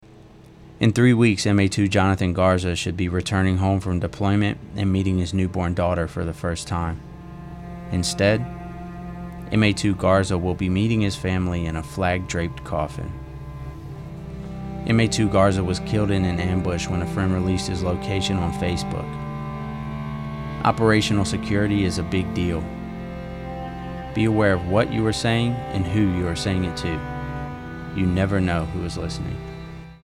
NAVAL AIR STATION SIGONELLA, Italy (Nov. 13, 2024) Radio spot highlights the consequences of not following OPSEC policy.